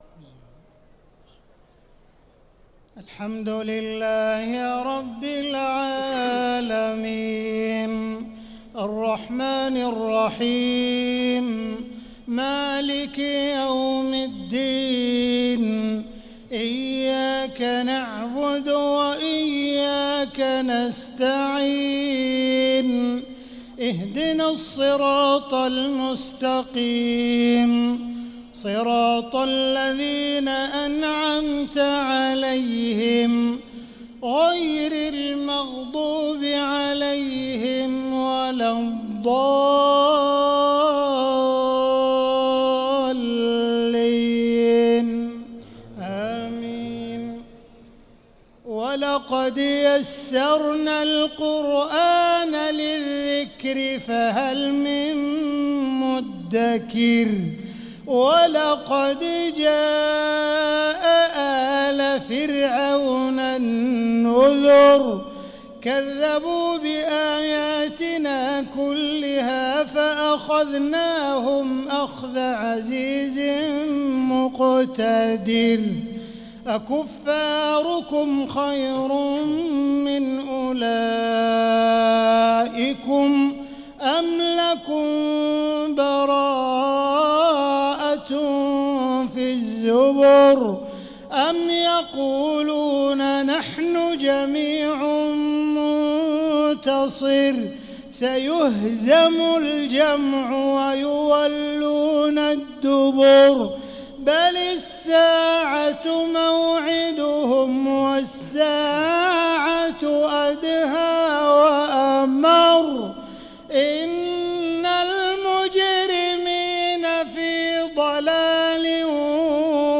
صلاة المغرب للقارئ عبدالرحمن السديس 23 رمضان 1445 هـ